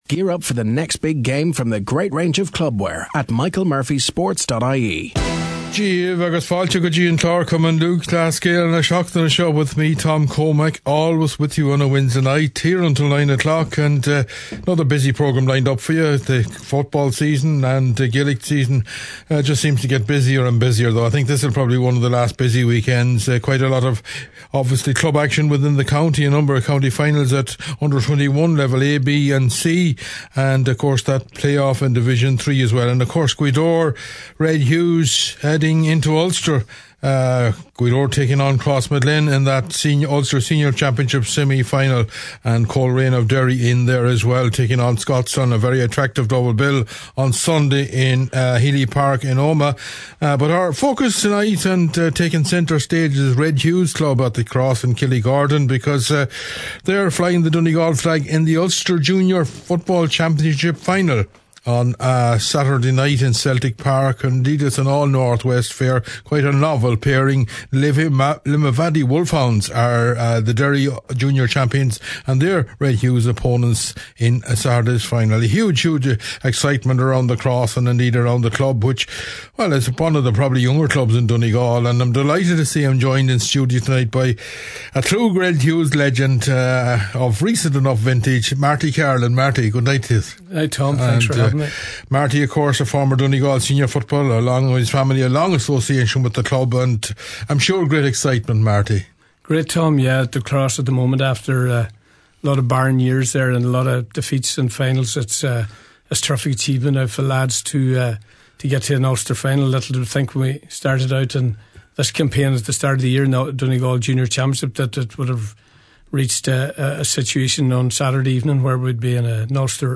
The GAA Programme in association with Michael Murphy Sports and Leisure, is broadcast every Wednesday from 8.30pm – 9pm.